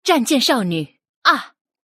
配音 赤崎千夏